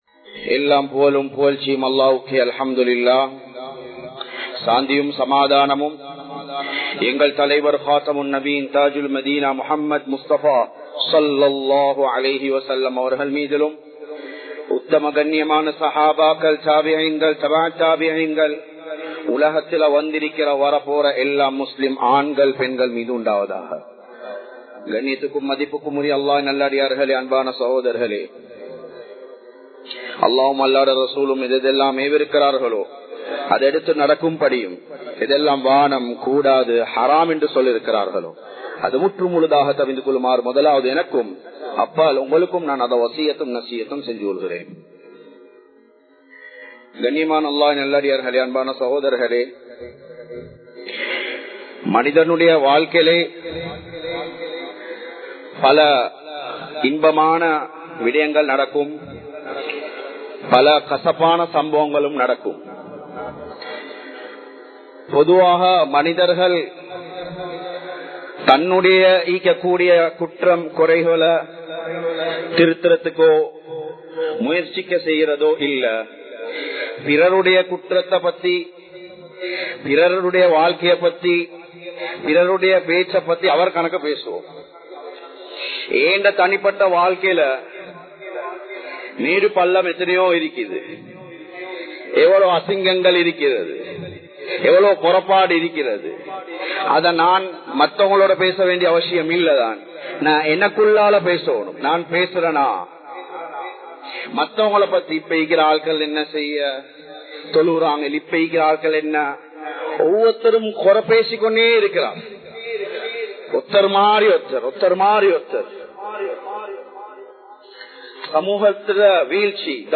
இனிமையான குடும்ப வாழ்க்கை | Audio Bayans | All Ceylon Muslim Youth Community | Addalaichenai
Muhiyadeen Jumua Masjith